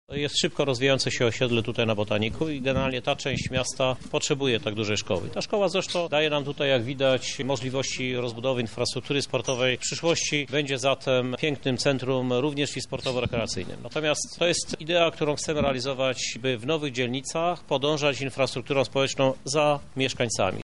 Myślę, że wszyscy z satysfakcją będą mogli korzystać z nowej szkoły- mówi Krzysztof Żuk prezydent Lublina: